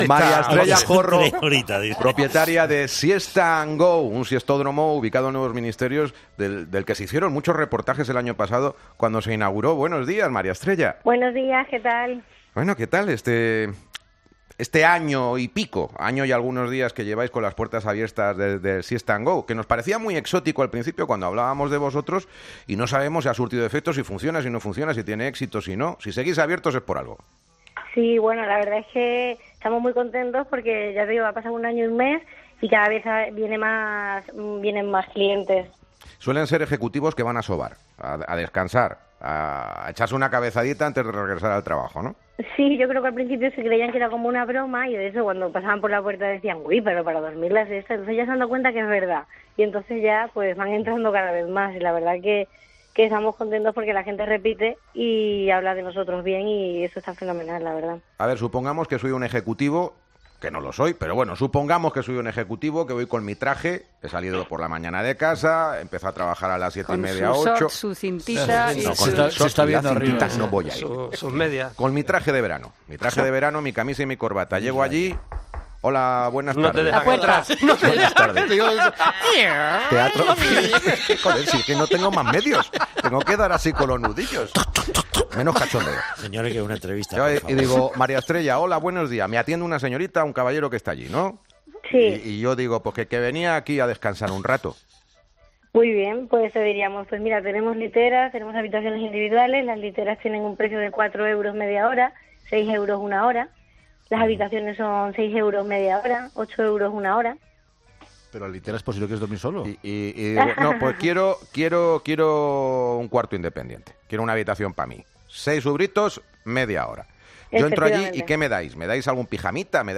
Herrera en COPE' Entrevista